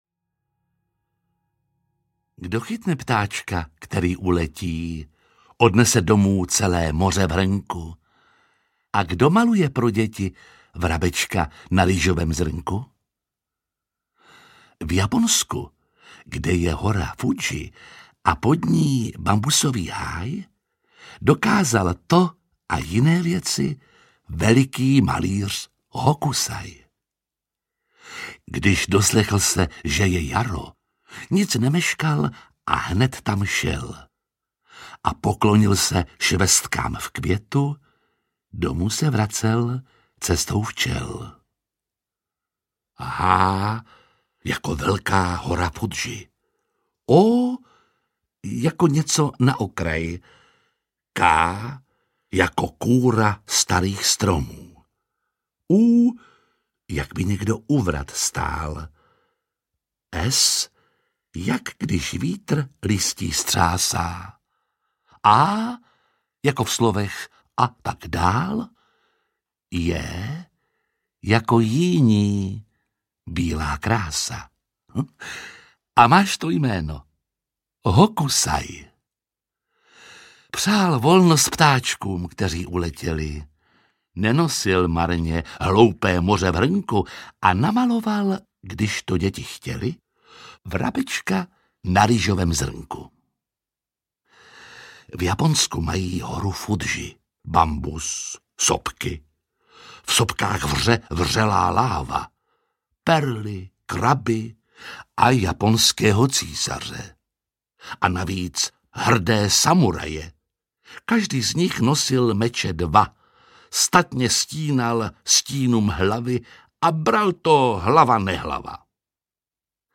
Uspávanka s Hokusajem audiokniha
Ukázka z knihy
• InterpretJiří Lábus